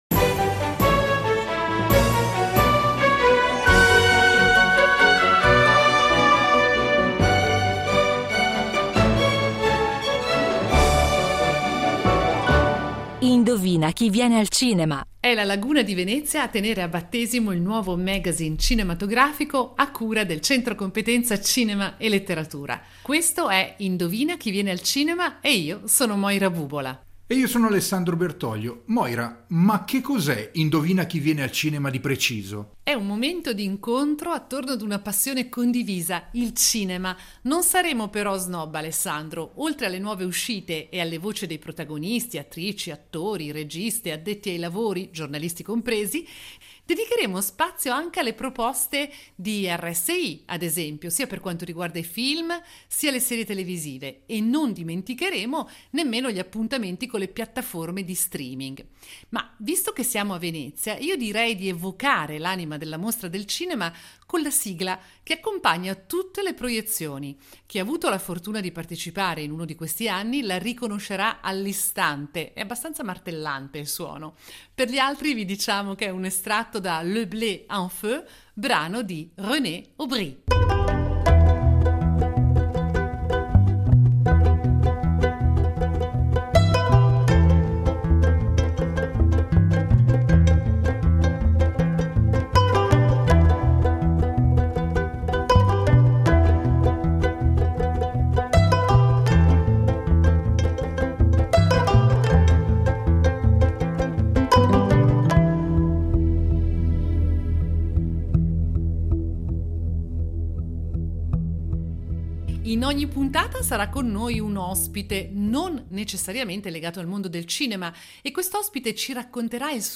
In diretta da Venezia